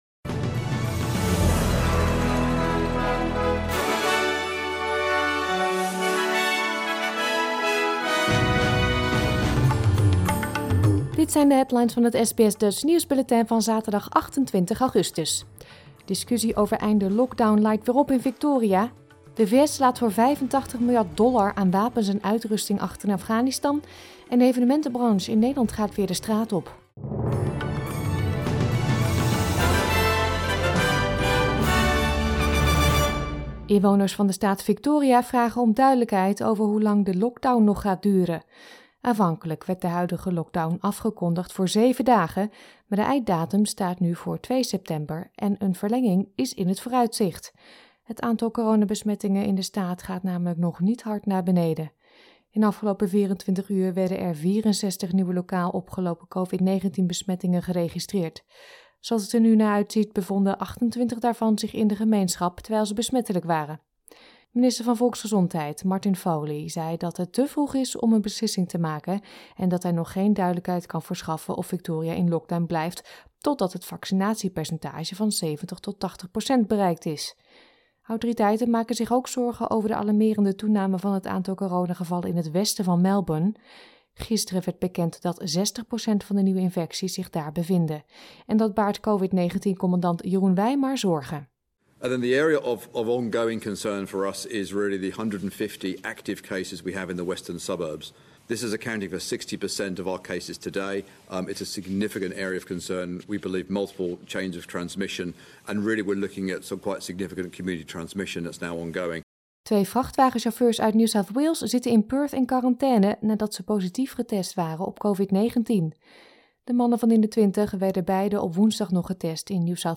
Nederlands/Australisch SBS Dutch nieuwsbulletin van zaterdag 28 augustus 2021